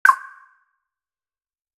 01Signal_Mashina.ogg